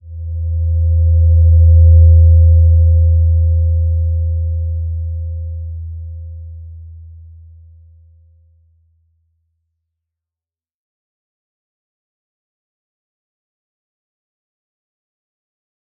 Slow-Distant-Chime-E2-mf.wav